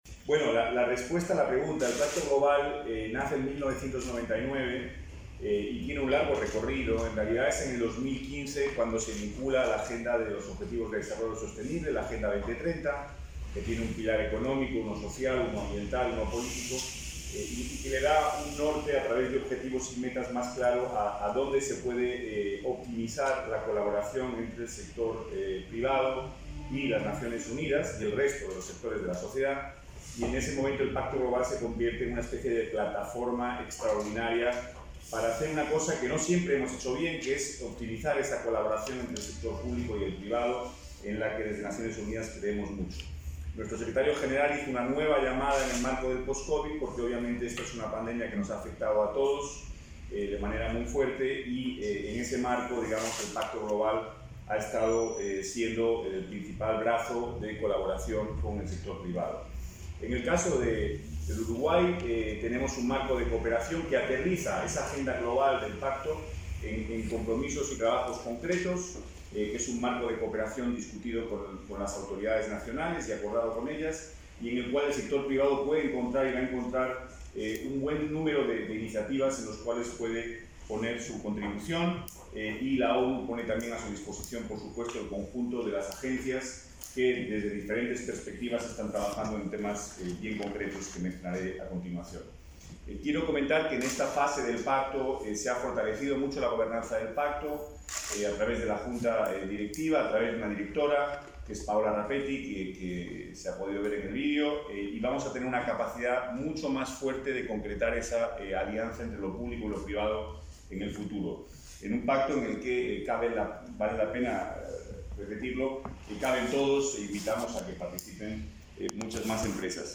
Palabras del coordinador residente de ONU en Uruguay, Pablo Ruiz Hiedra, y del director ejecutivo de AUCI, Mariano Berro
Este jueves 19, el coordinador residente de Naciones Unidas en Uruguay, Pablo Ruiz Hiedra, y el director ejecutivo de la Agencia Uruguaya de Cooperación Internacional (AUCI), Mariano Berro, participaron del lanzamiento, en nuestro país, del Pacto Global de Naciones Unidas, la mayor red de sostenibilidad empresarial del mundo.